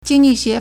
经济学 (經濟學) jīngjì xué
jing1ji4xue2.mp3